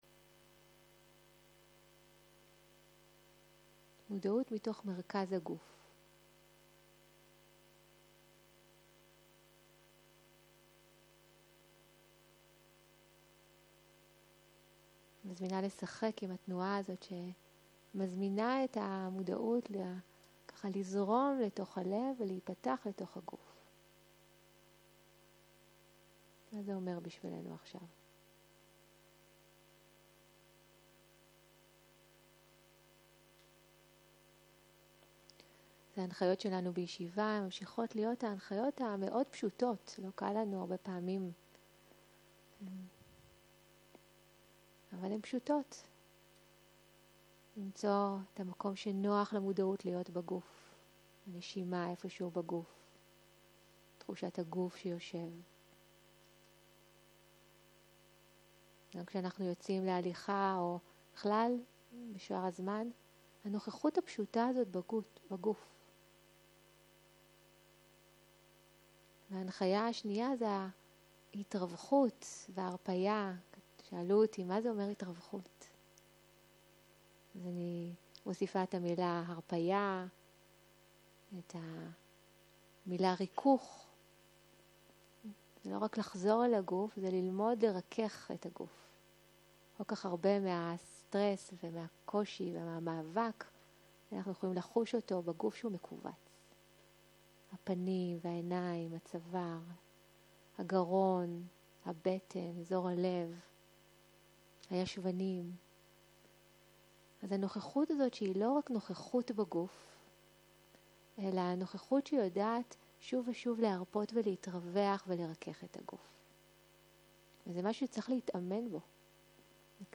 יום 3 - בוקר - הנחיות מדיטציה - נוכחות פשוטה בגוף - הקלטה 4